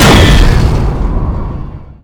bladeslice1.wav